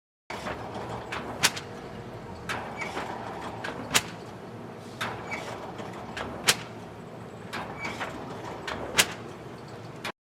Paper Press
Paper Press is a free foley sound effect available for download in MP3 format.
yt_NgeoqWbRsmA_paper_press.mp3